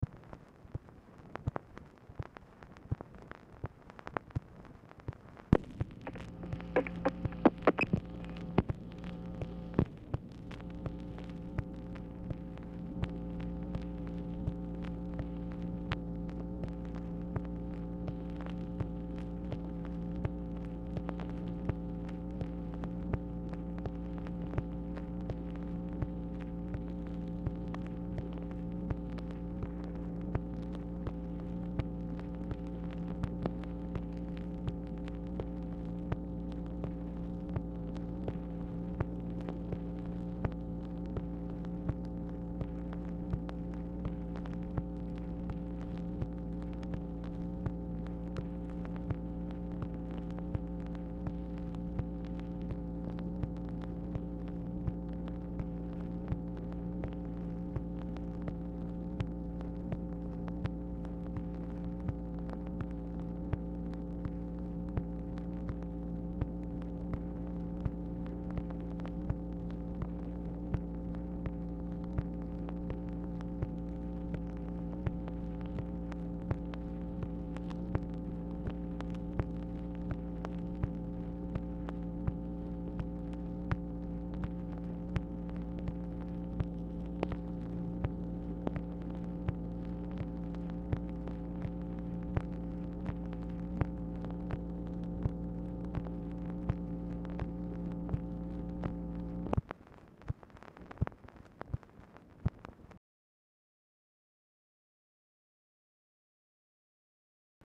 Telephone conversation # 11140, sound recording, MACHINE NOISE, 12/14/1966, time unknown | Discover LBJ
Telephone conversation
Format Dictation belt